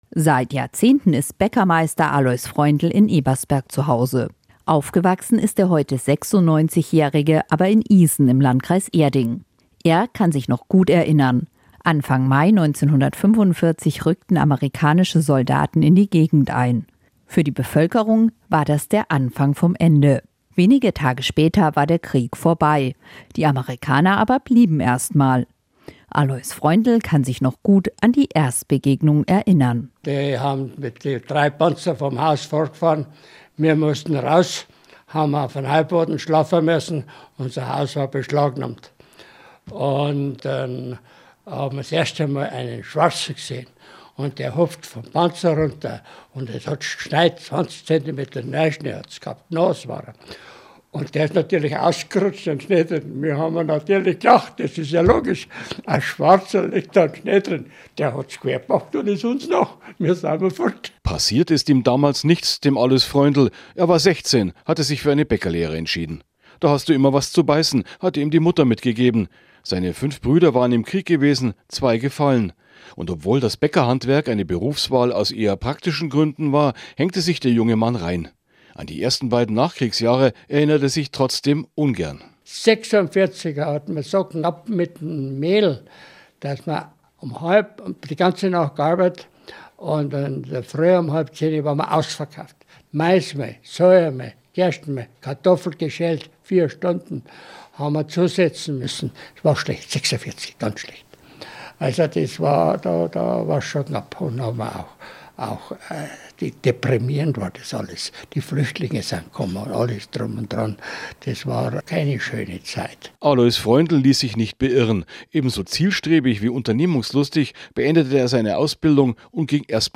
Wir haben Interviews mit beeindruckenden Persönlichkeiten geführt, die ihre Erinnerungen teilen.